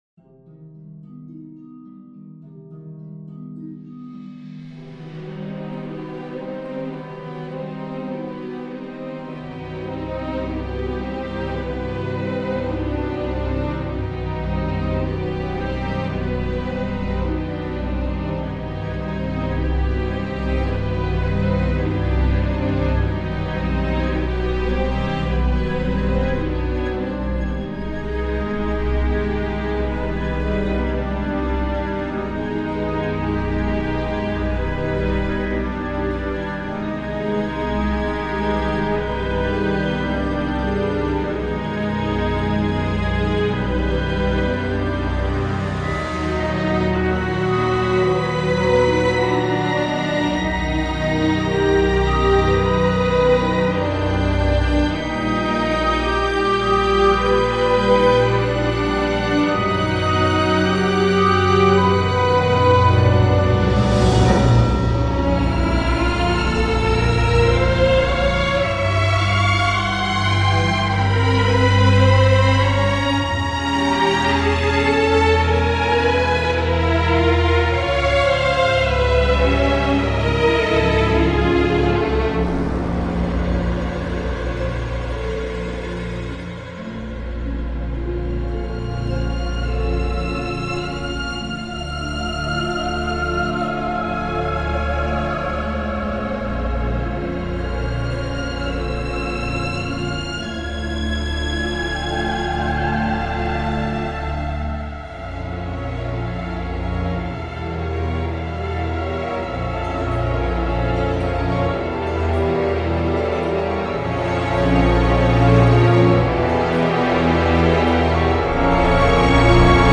une musique sombre, torturée et anxiogène